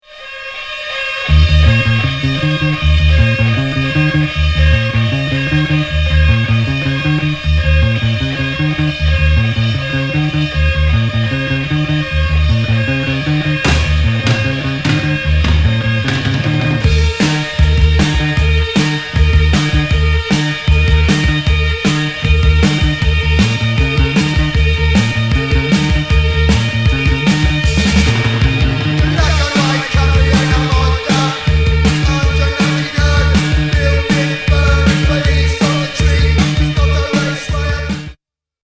まるでサイレンのような不穏なギターから始まる
ノイジーなギター、レゲー・ライクなベース、
なんにせよ当時のヒリついた感満点のパンキッシュ・レゲー隠れ傑作！！